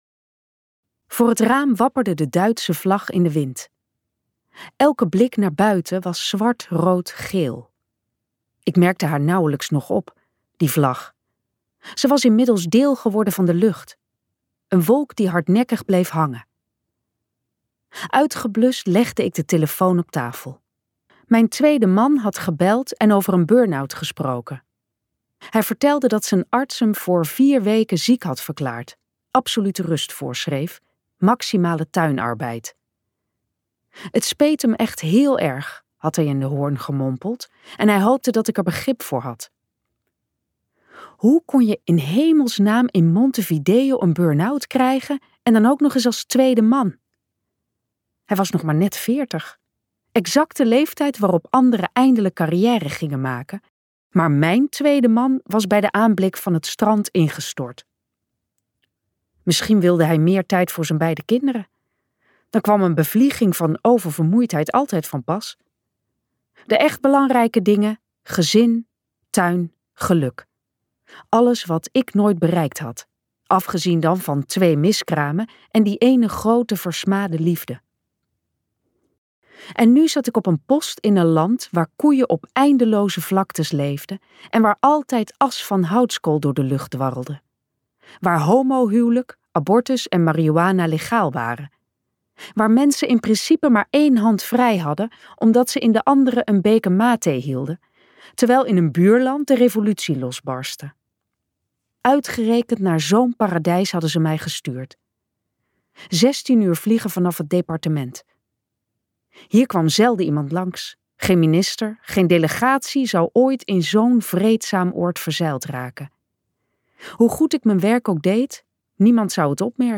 Ambo|Anthos uitgevers - De diplomate luisterboek